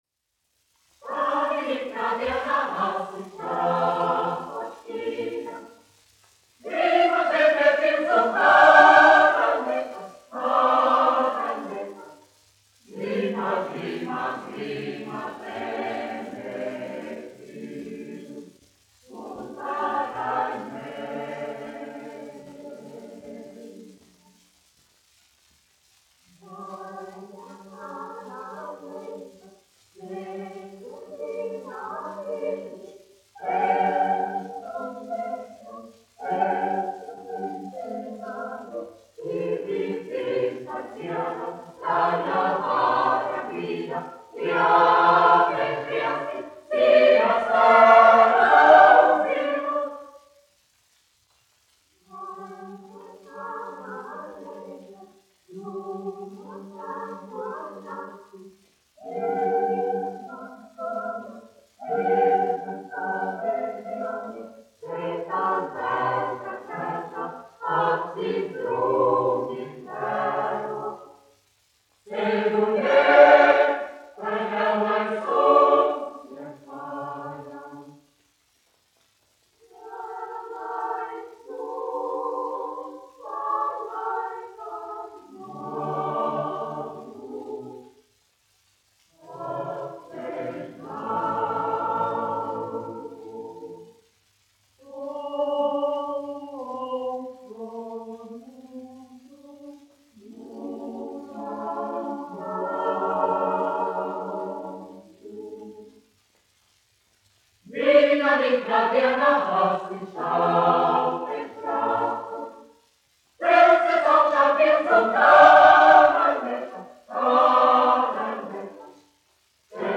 1 skpl. : analogs, 78 apgr/min, mono ; 25 cm
Kori (jauktie)
Latvijas vēsturiskie šellaka skaņuplašu ieraksti (Kolekcija)